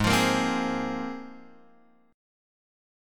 G#6add9 chord